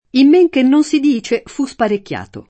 meno [m%no] avv. — tronc. nelle locuz. in men che non si dica, men che meno, men che mai, men che corretto (e sim.): in men che non si dice, fu sparecchiato [